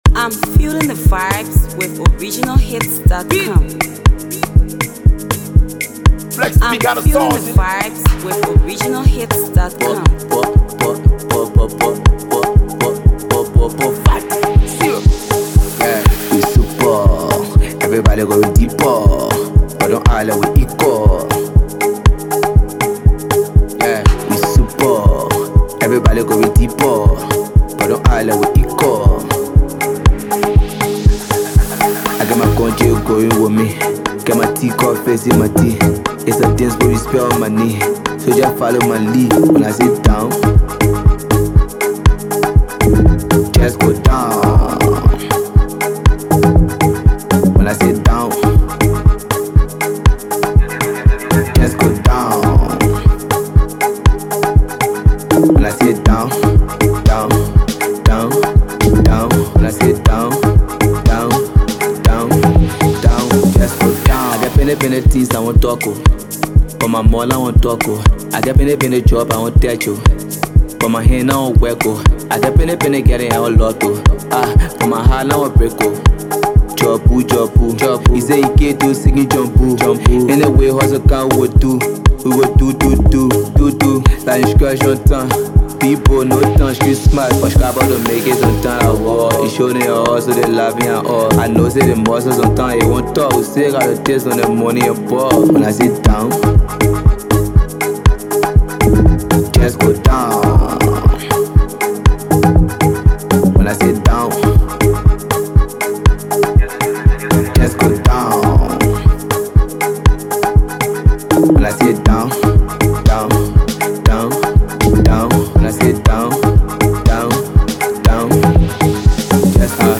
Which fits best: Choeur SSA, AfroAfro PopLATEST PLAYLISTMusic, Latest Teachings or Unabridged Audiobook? AfroAfro PopLATEST PLAYLISTMusic